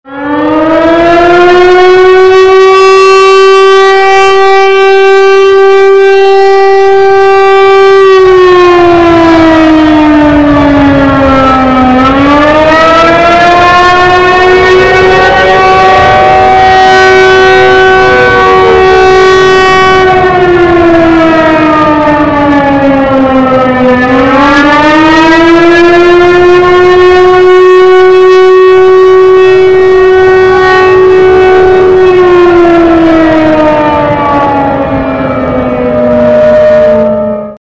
zAirRaid.mp3